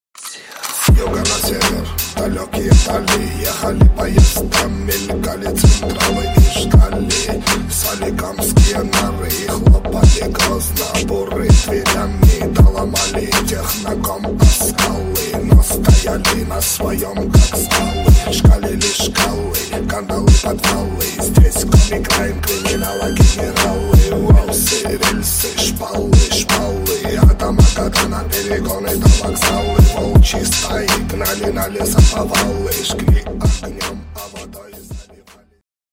ремиксы
блатные , рэп